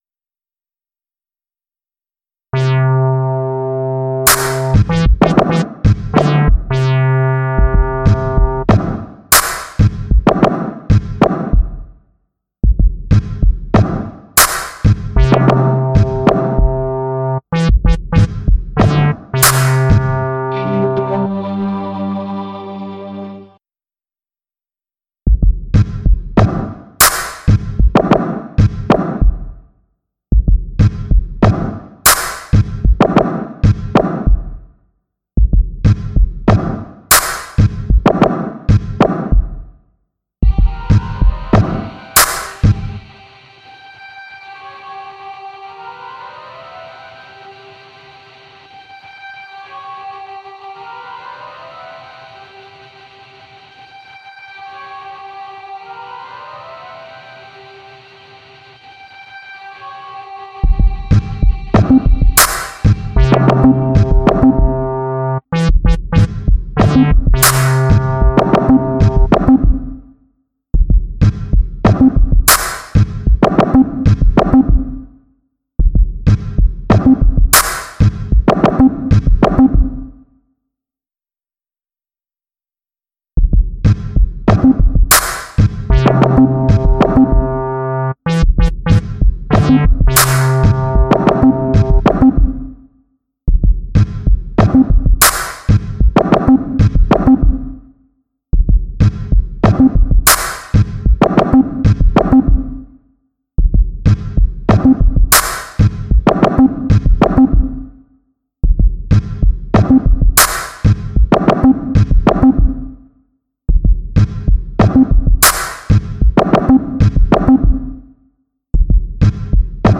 Nur Beat oben - kein Punkt Nur Beat oben - kein Punkt Nur Beat oben …